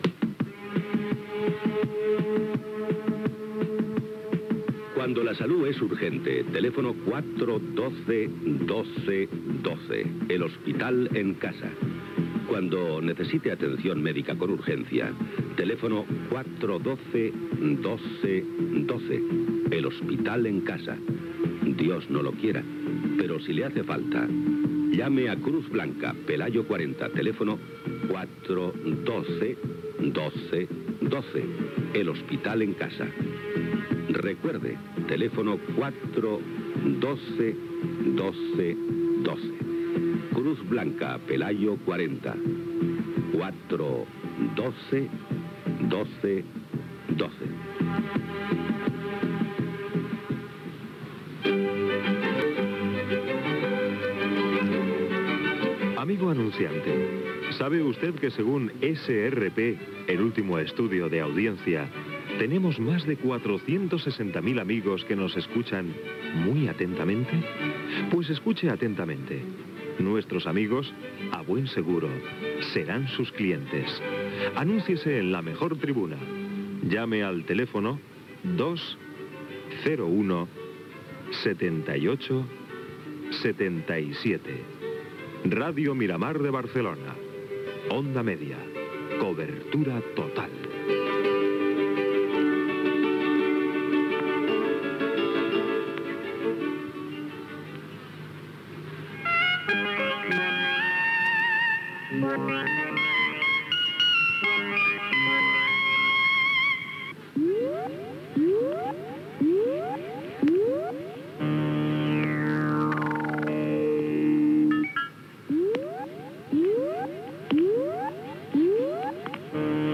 Publicitat, dades d'audiència de Radio Miramar, sintonia del programa, hora, presentació, avís que l'emissió a Madrid es tallarà aquella matinada, comentari sobre els gnòstics i invitació a participar en el programa.
Divulgació